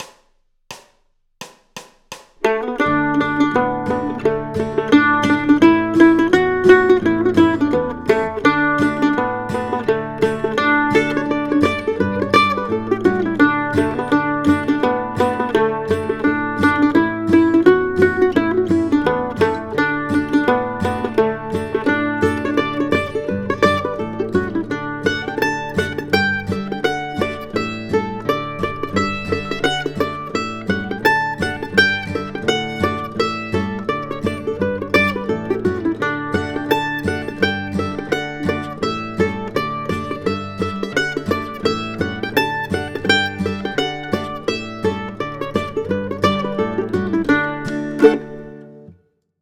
standard fiddle tune, D